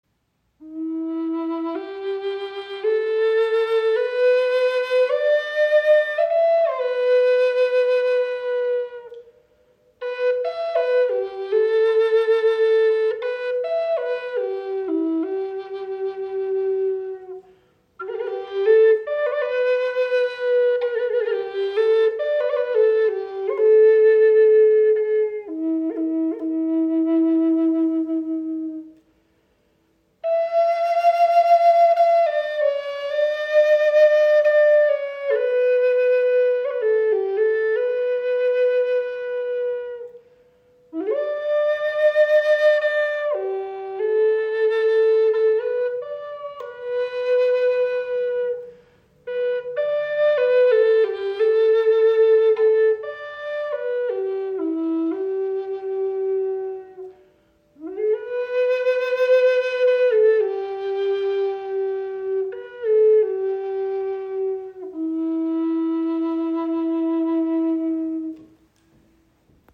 • Icon Gesamtlänge 61  cm, 25,4 mm Innenbohrung – warmer, klarer Klang
In E-Moll gestimmt entfaltet sie einen sanften, meditativen Klang, der Dich sofort in innere Ruhe führt. Ihr süsser, klarer Ton lädt Dich ein, den Atem als Gebet zu spüren und Dich tief mit Deinem Herzen zu verbinden.
Warmer, klarer Klang, präzise Intonation und intuitive Spielbarkeit.